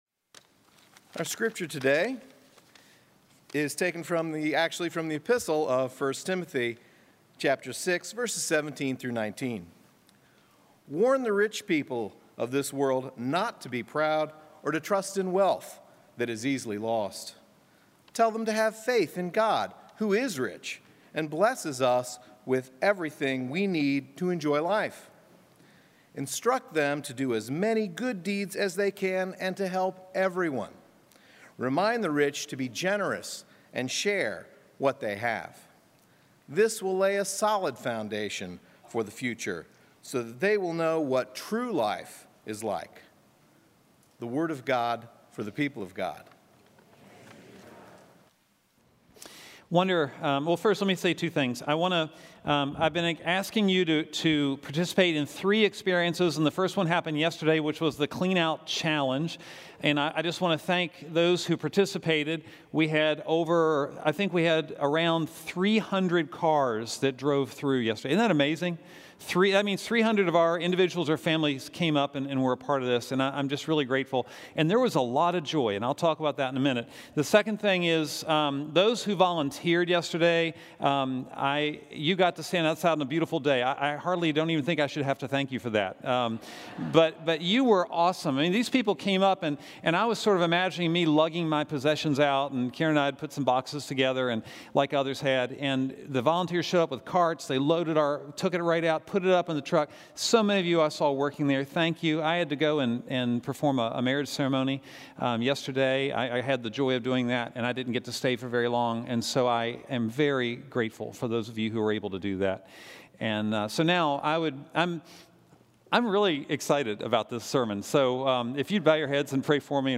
Scripture: Luke 12:13-21, The goal of this sermon is to put money in your pocket. People struggle with generosity not because they lack the desire, but because they have not created the ability.